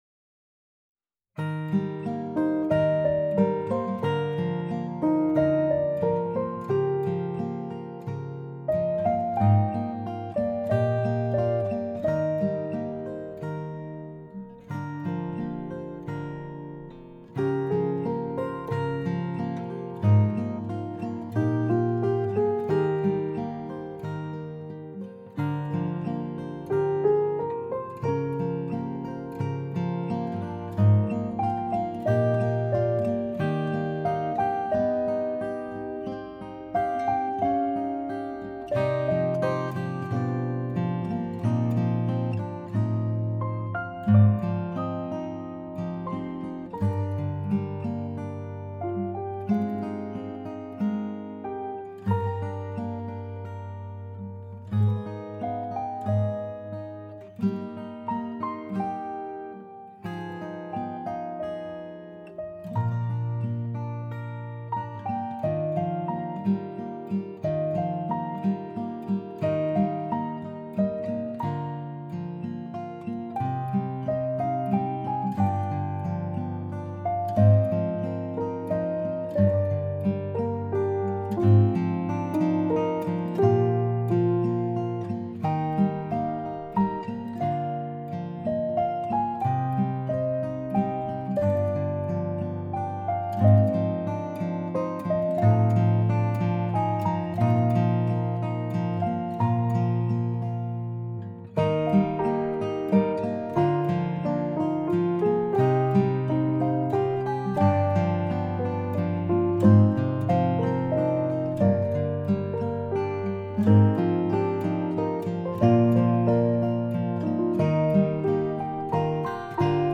You Were There Piano Guitar 2019
you-were-there-piano-guitar-1-2-19-2.mp3